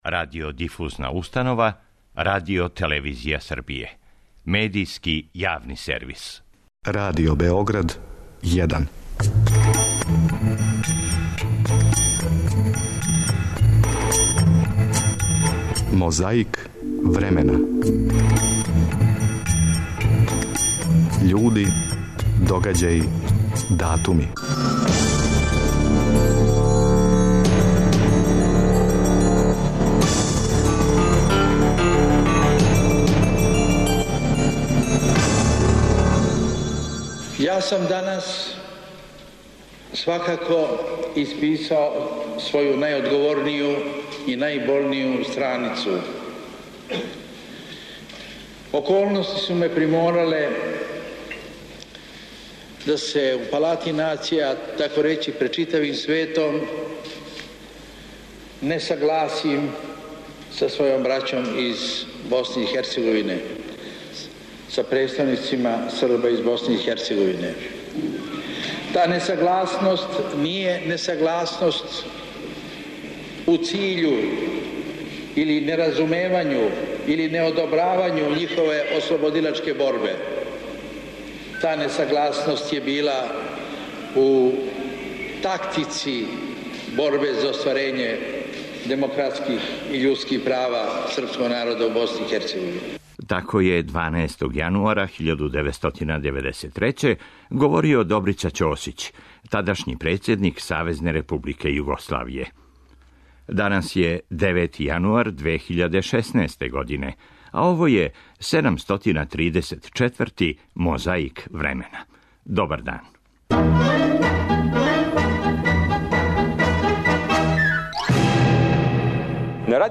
На почетку емисије, слушао како је 12. јануара 1993. говорио Добрица Ћосић.
Говорио је Зоран Ђинђић.
Подсећа на прошлост (културну, историјску, политичку, спортску и сваку другу) уз помоћ материјала из Тонског архива, Документације и библиотеке Радио Београда.